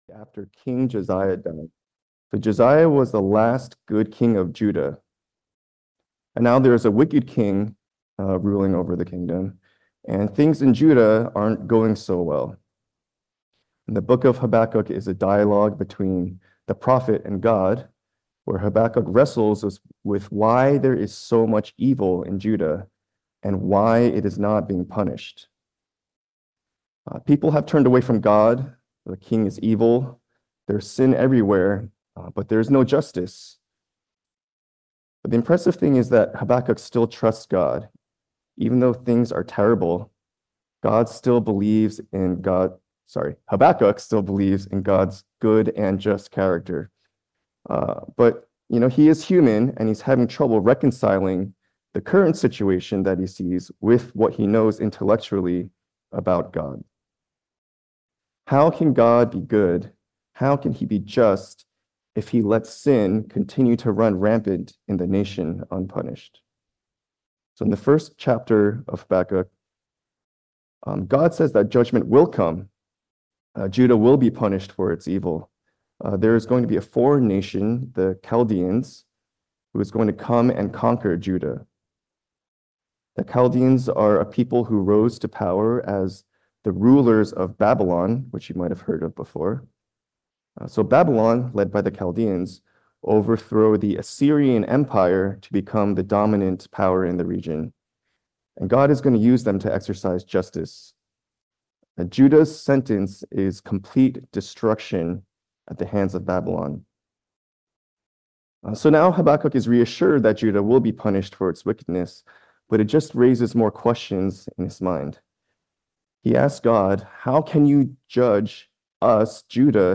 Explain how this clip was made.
2024 (Sunday Service)Bible Text